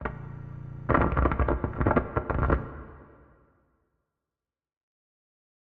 Minecraft Version Minecraft Version snapshot Latest Release | Latest Snapshot snapshot / assets / minecraft / sounds / ambient / nether / warped_forest / mood5.ogg Compare With Compare With Latest Release | Latest Snapshot